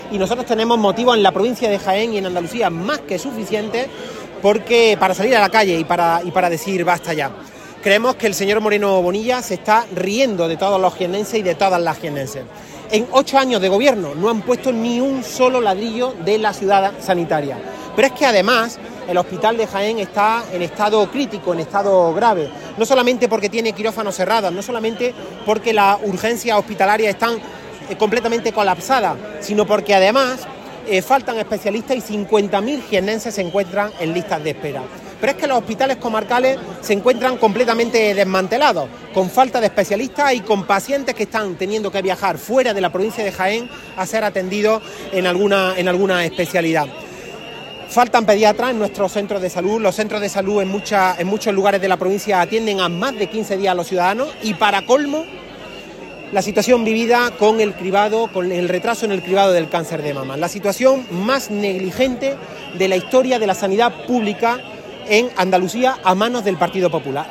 Manifestación de las Mareas Blancas contra la privatización de la sanidad
Cortes de sonido